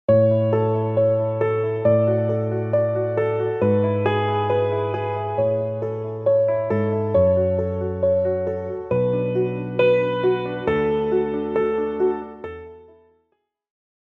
Piano positive classical instrumental background music